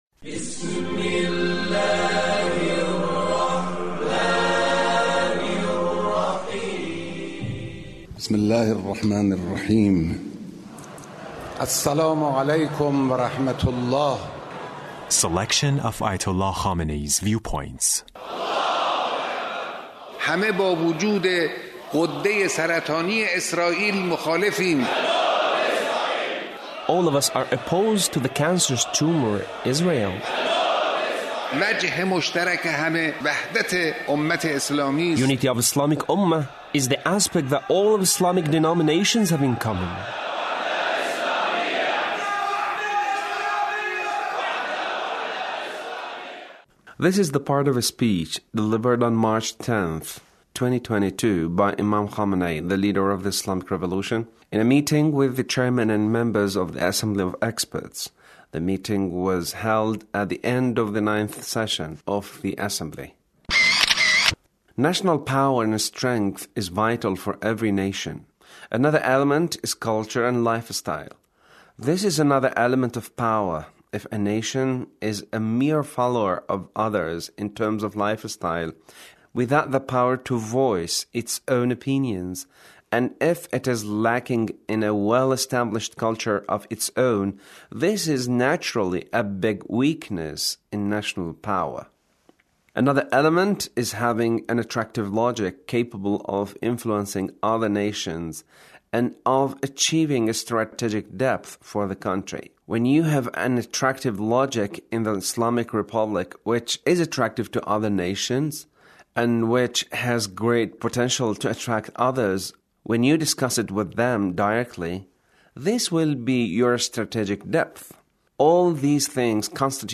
The Leader's speech on Assembly of Experts